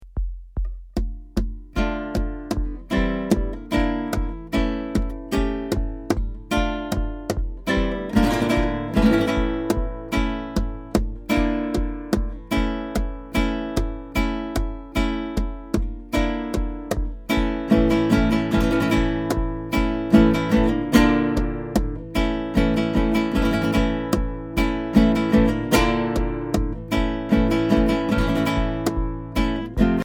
Voicing: Guitar Collection